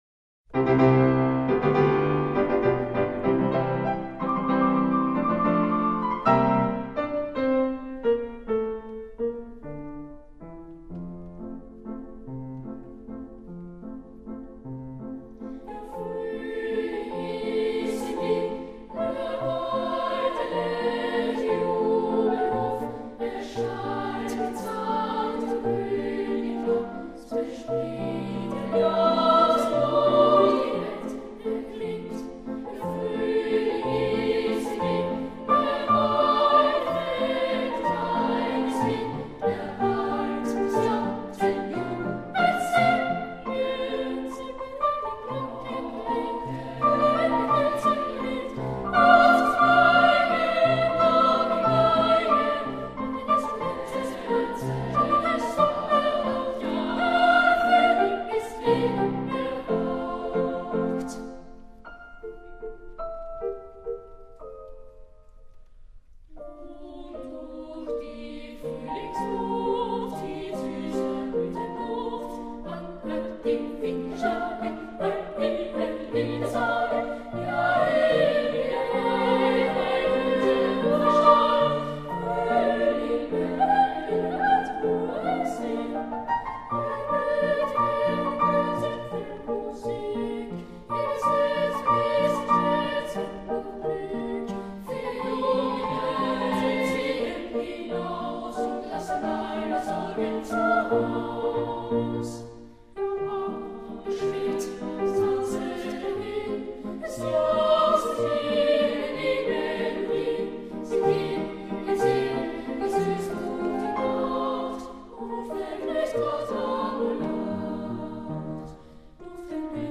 类型：古典
五百多年来，经历了战争、瘟疫，甚至哈布斯堡王朝的覆灭，唯一不变的是他们纯净无垢天使般的歌声。